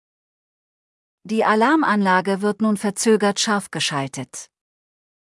Einbruchalarm
Alarmanlage-geschaltet.mp3